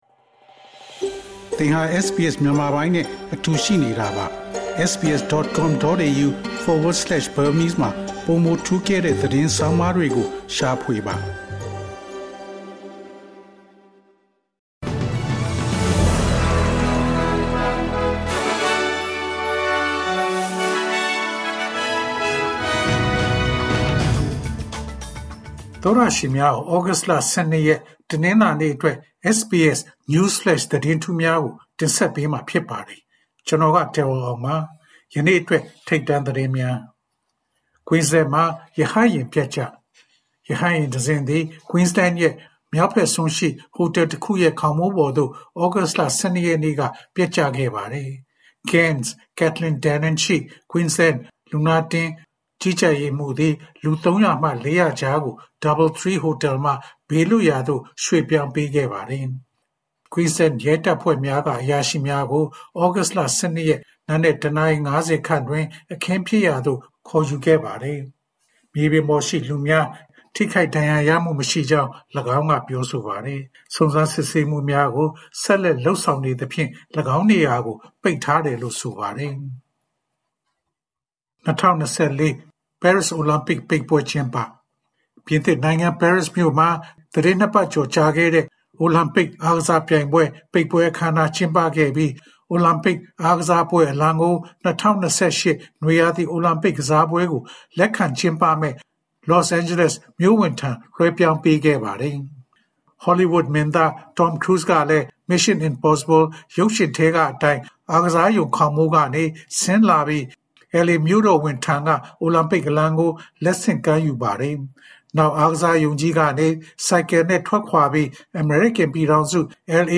ဩဂုတ်လ ၁၂ ရက် တနင်္လာနေ့ SBS Burmese News Flash သတင်းများ။